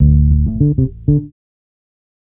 Bass Lick 34-03.wav